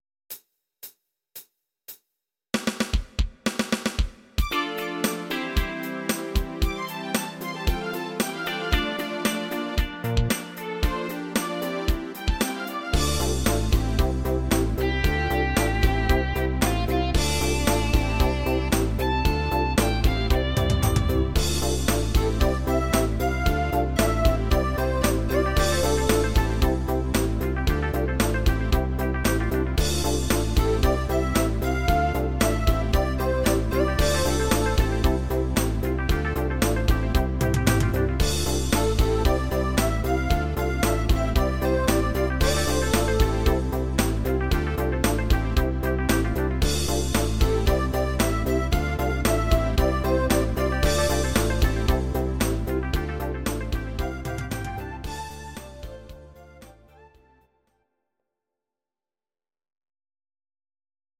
Audio Recordings based on Midi-files
Our Suggestions, Pop, 1980s